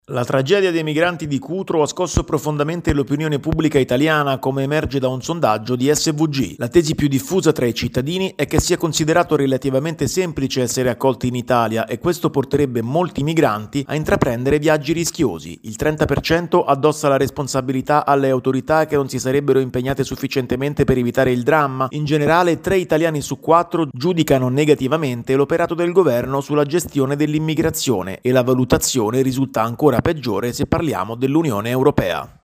Tre italiani su 4 giudicano negativamente l’operato del Governo sulla gestione dell’immigrazione. Il servizio